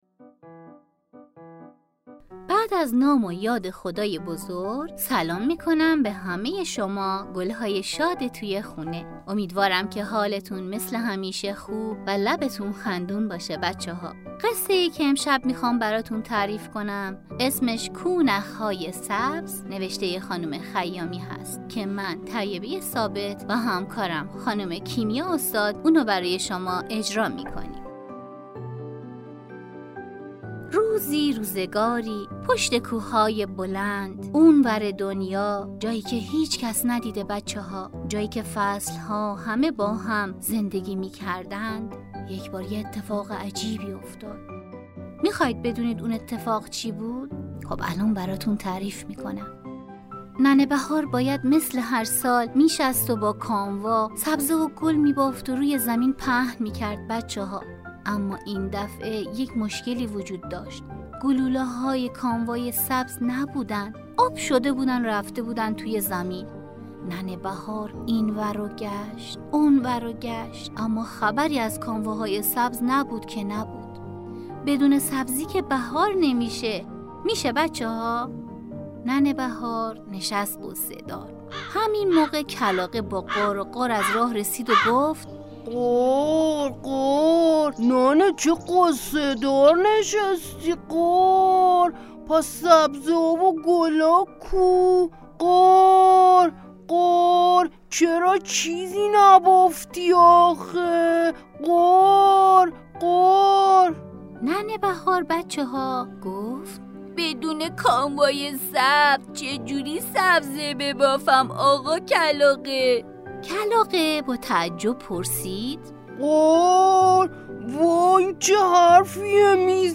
کودک / چند رسانه‌ای
پادکست داستانی | «کو نخ‌های سبز» اثر «لیلا خیامی»